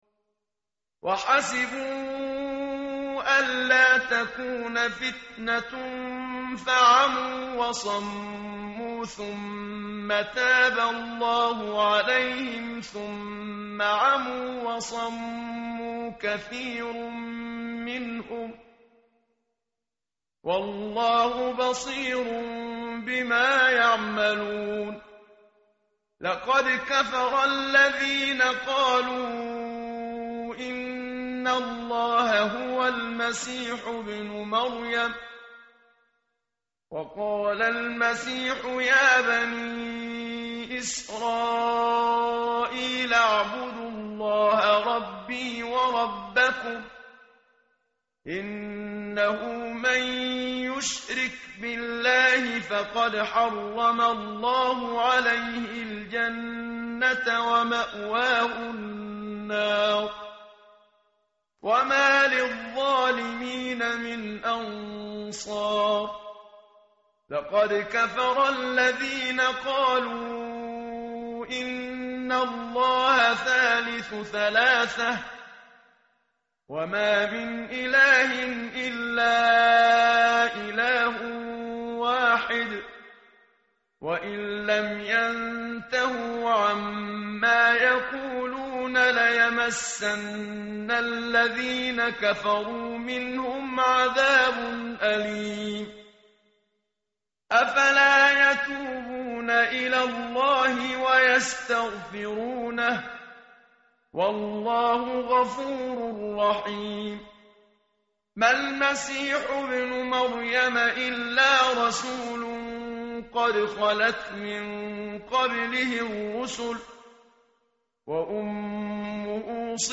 tartil_menshavi_page_120.mp3